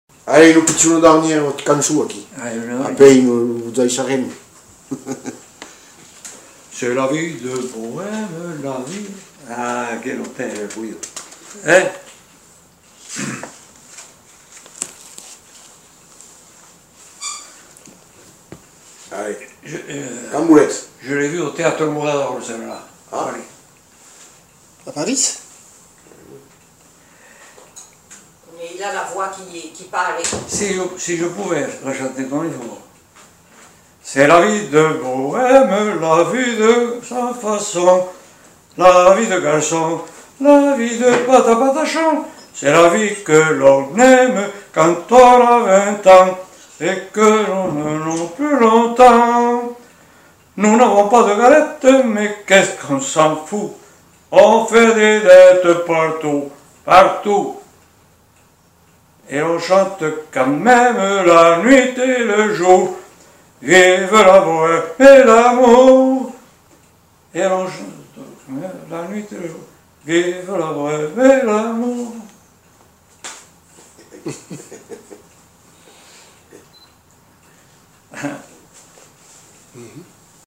Aire culturelle : Lauragais
Lieu : Le Faget
Genre : chant
Effectif : 1
Type de voix : voix d'homme
Production du son : chanté